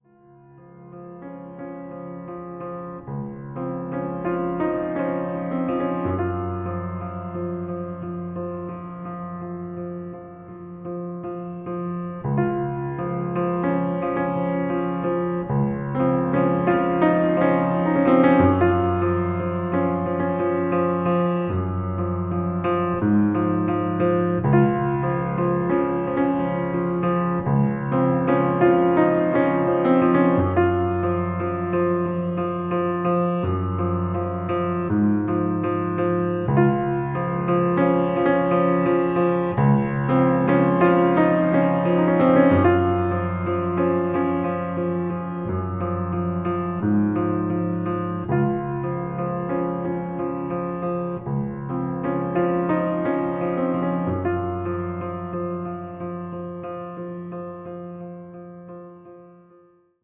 pianoforte
dall'atmosfera malinconica ma affascinante